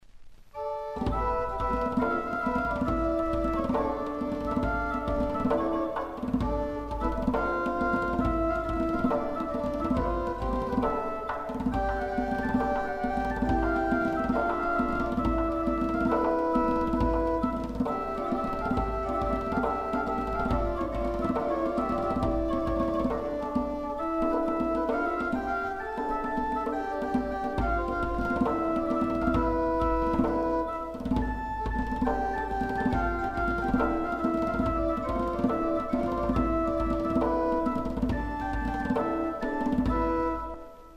Manuscrit de Robertsbridge Abbey, 14e siècle